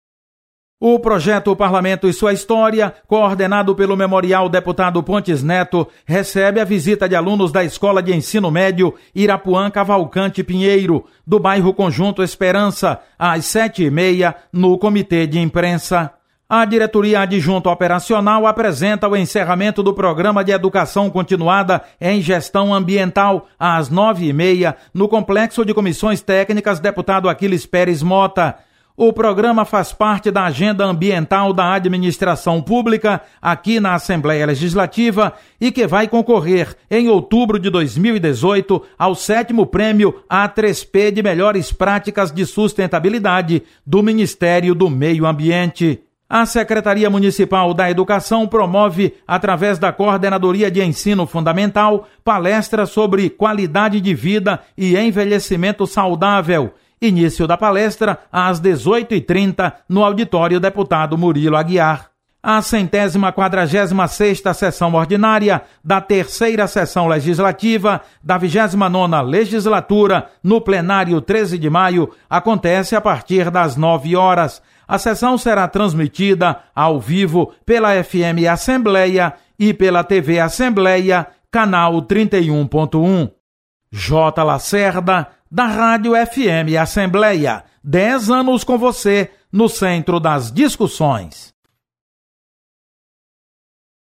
Acompanhe as atividades desta quarta-feira (22/11) da Assembleia Legislativa. Repórter